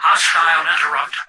"Hostile interrupt" excerpt of the reversed speech found in the Halo 3 Terminals.